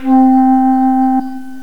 piccolo.mp3